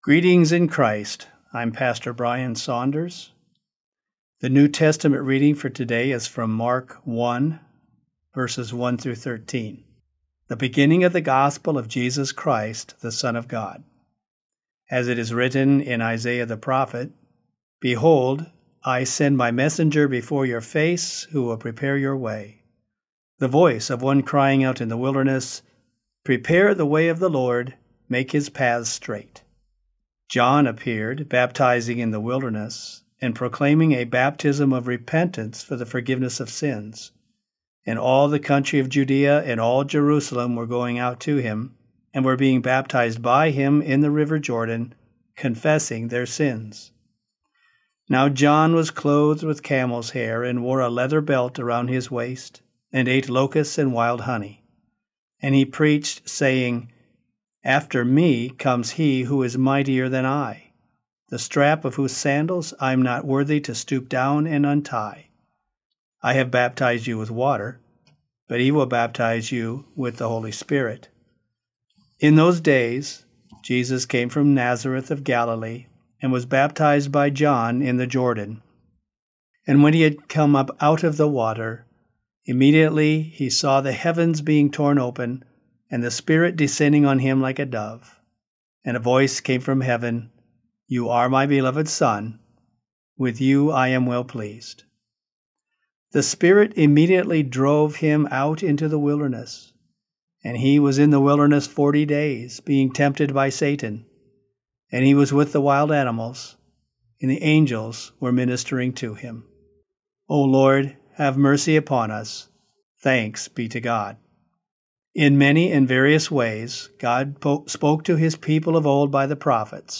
Morning Prayer Sermonette: Mark 1:1-13
Hear a guest pastor give a short sermonette based on the day’s Daily Lectionary New Testament text during Morning and Evening Prayer.